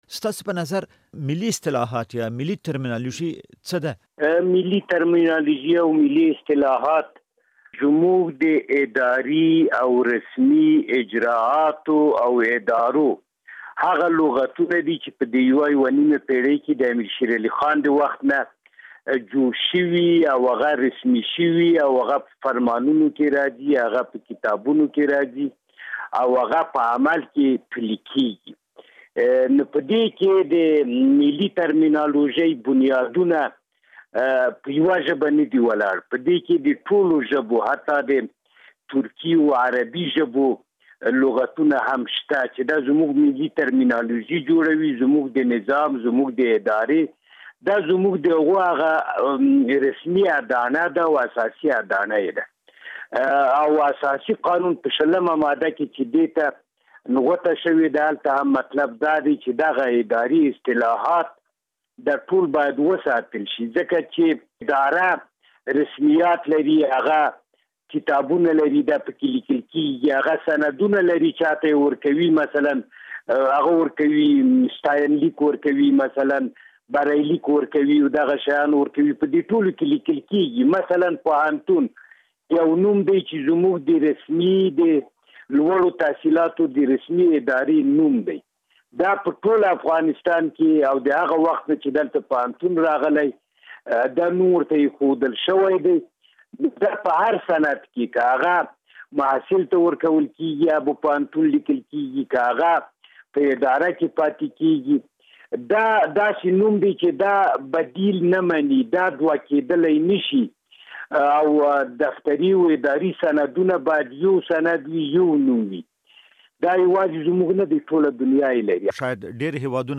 له حبيب الله رفېع سره مرکه